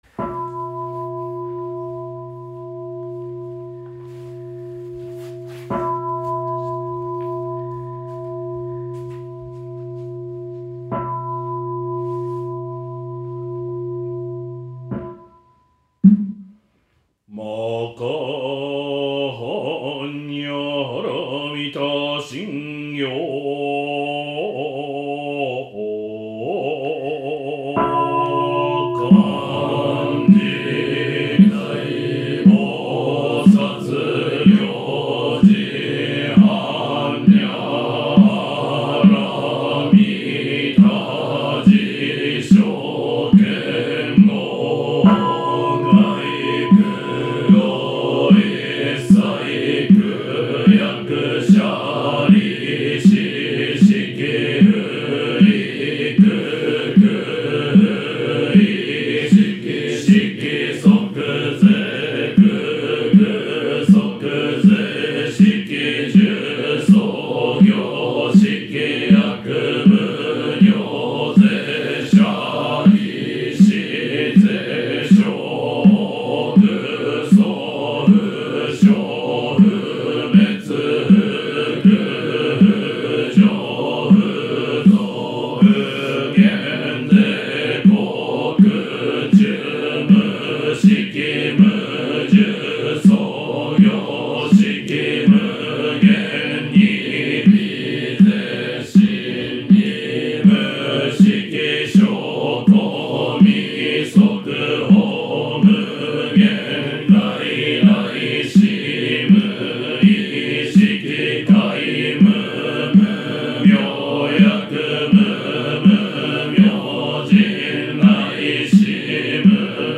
多くの方に「お経」に親しみ、お経を覚えていただきたいとの思いから、10月11日、永隣寺寺族の一周忌法要に集まった僧侶で、代表的なお経である「般若心経」の録音をしました。
初めての方でも一緒に読めるように、ゆっくりしたスピードで唱えています。